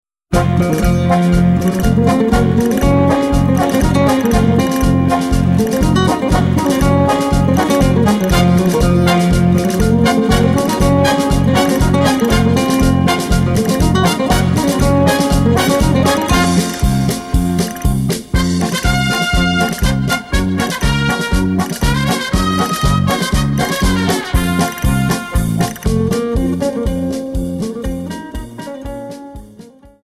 Paso Doble 60 Song